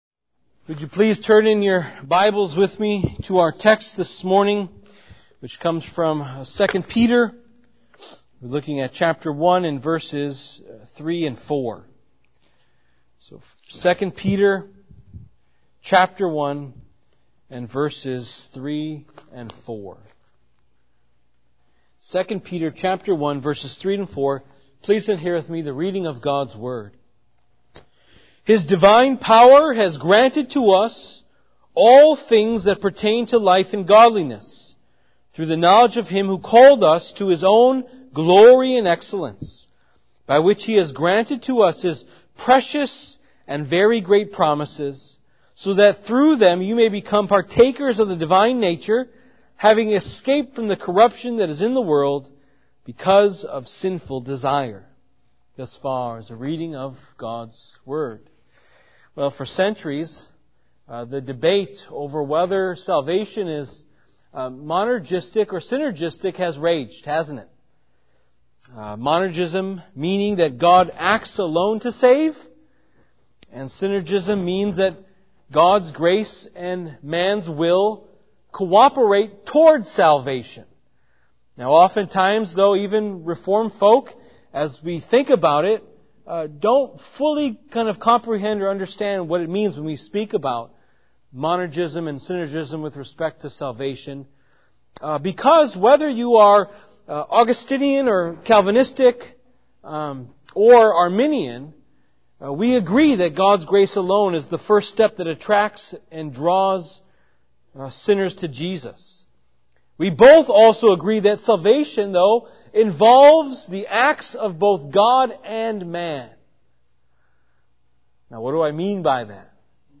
we-have-all-we-need-in-christ-sermon-2-peter-1-3-4.mp3